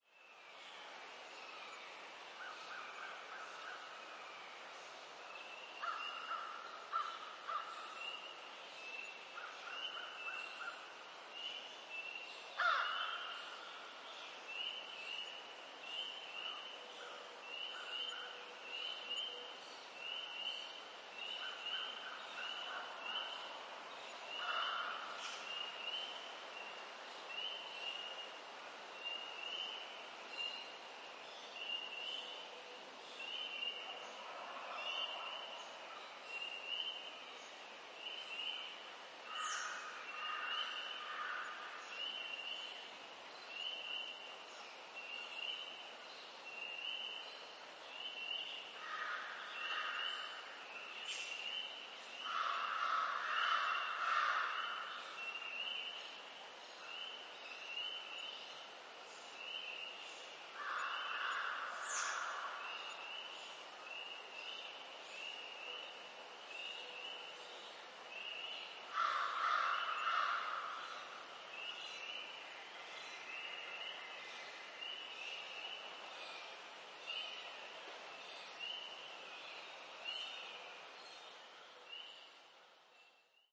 自然环境 " 康涅狄格州的黎明鸟
描述：森林地鸟在黎明之后在中央康涅狄格森林里。与零星乌鸦电话的鸟背景。用舒尔SM81重合对/ Amek 9098 DMA前置放大器录制。
Tag: 性质 昆虫 现场记录 森林 青蛙